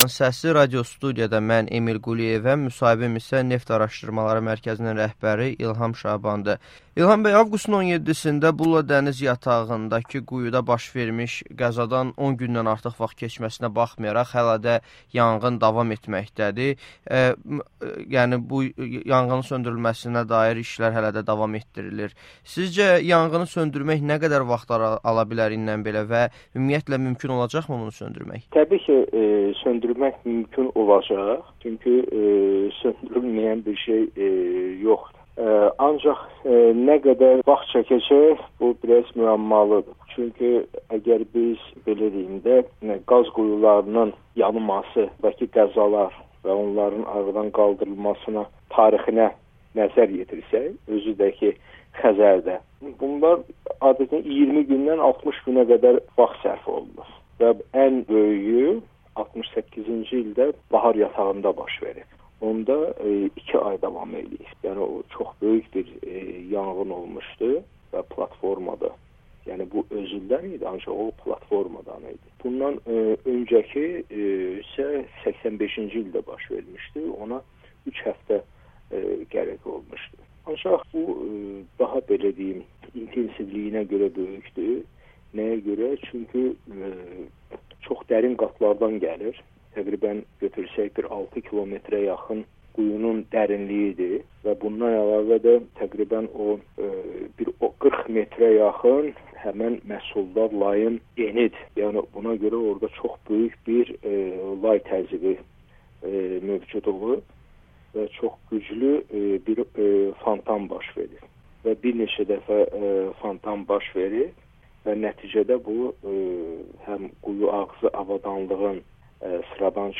Amerikanın Səsinə müsahibədə şərh edib.